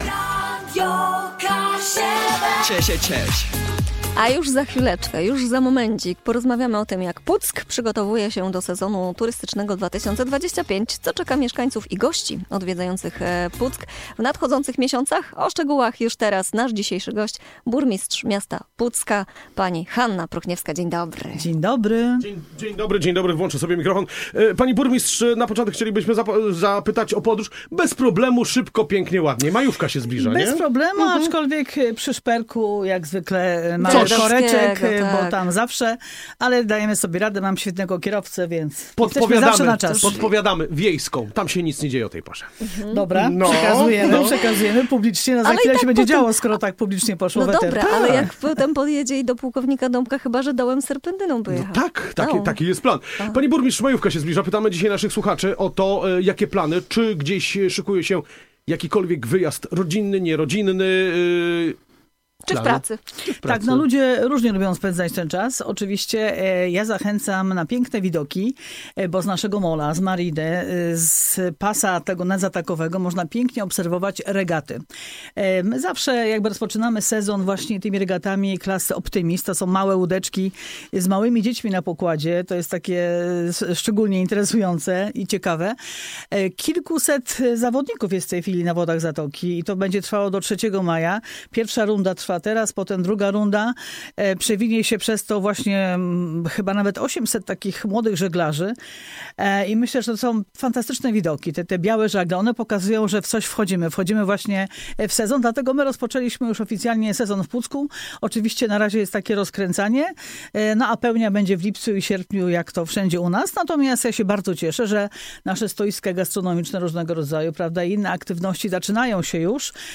Burmistrz Hanna Pruchniewska w rozmowie z Radiem Kaszëbë zdradziła szczegóły bogatego programu atrakcji turystycznych na sezon 2025.